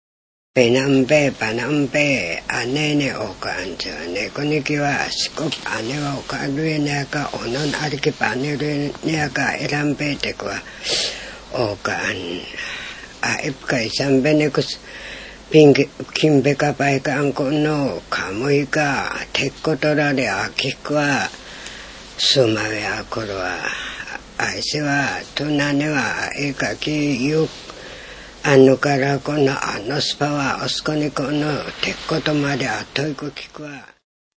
• ジャンル：民話　ウウェペケㇾ／アイヌ語